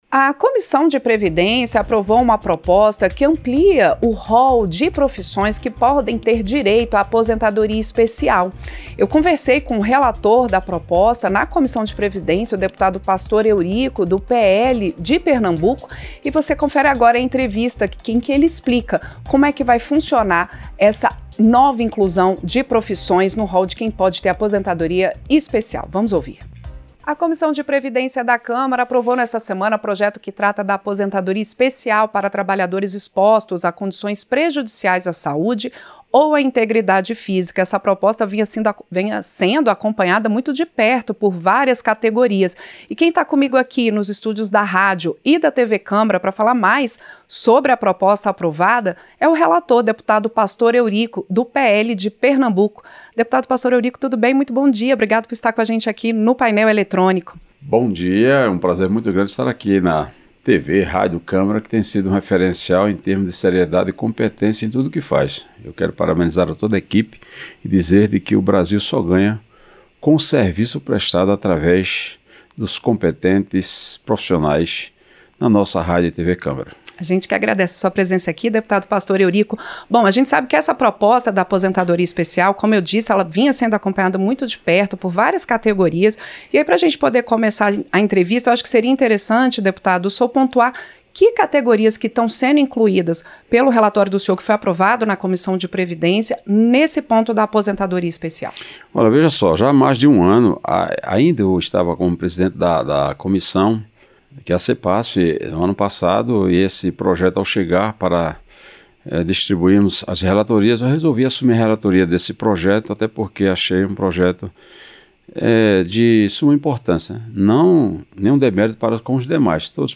Entrevista - Dep. Pastor Eurico (PL-PE)